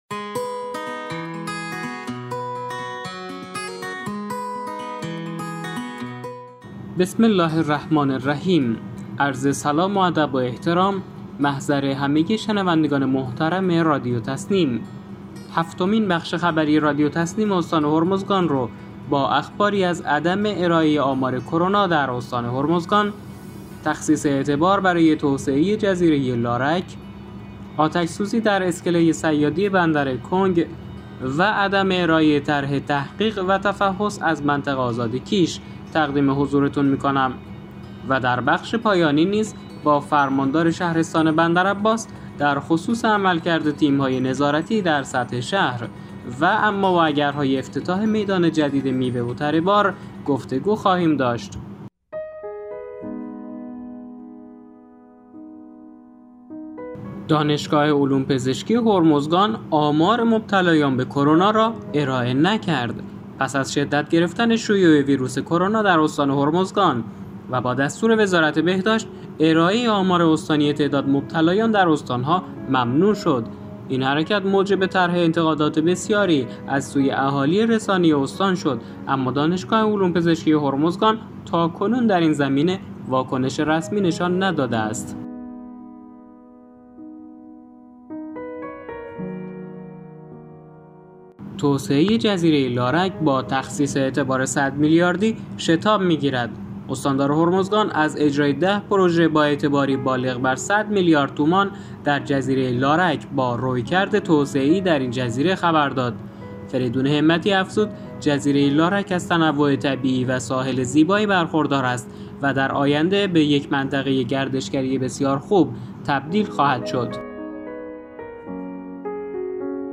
به گزارش خبرگزاری تسنیم از بندرعباس، هفتمین بخش خبری رادیو تسنیم استان هرمزگان با اخباری از عدم ارائه آمار کرونا در استان هرمزگان از سوی دانشگاه علوم پزشکی، تخصیص اعتبار برای توسعه جزیره لارک، آتش‌سوزی در اسکله صیادی بندرکنگ و عدم ارائه طرح جدید تحقیق و تفحص از منطقه آزاد کیش و گفتگوی ویژه خبری با فرماندار شهرستان بندرعباس در خصوص عملکرد تیم‌های نظارتی در سطح شهر و اما و اگرهای افتتاح میدان جدید میوه و تره بار منتشر شد.